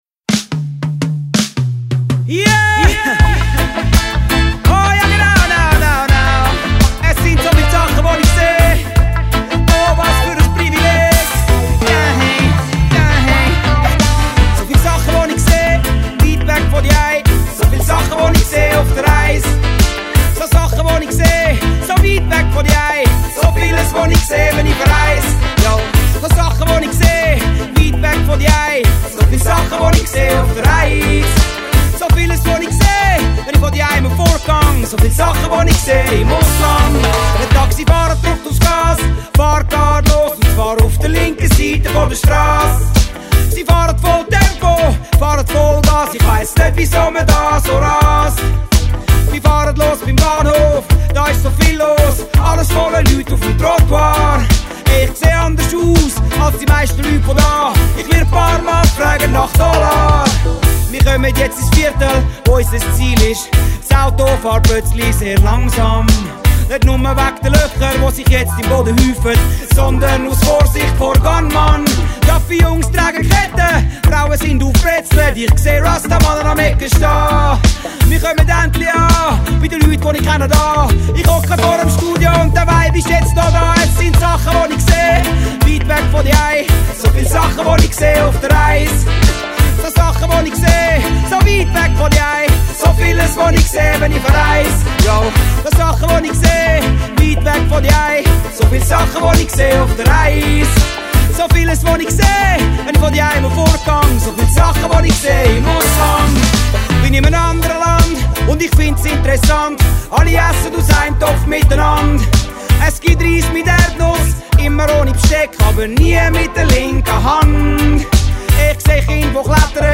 Reggae.
enriched with original lyrics sung in Swiss-German dialect.